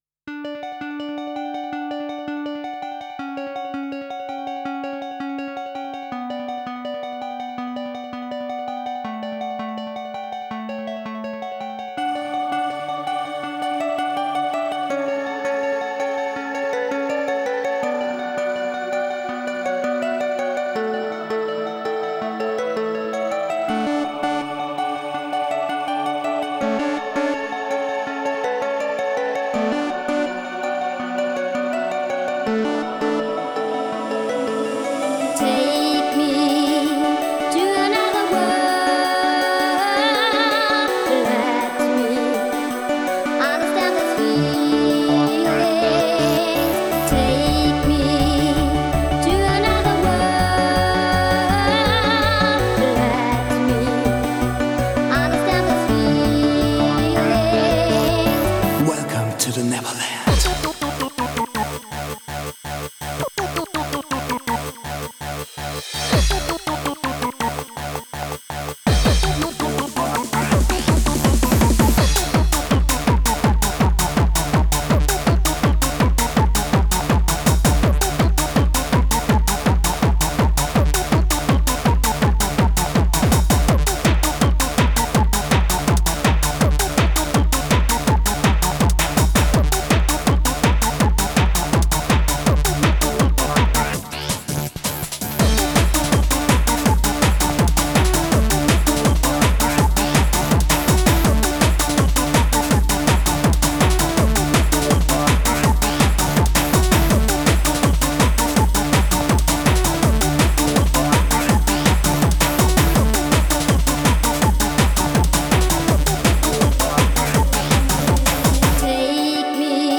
Genre: Happy Hardcore.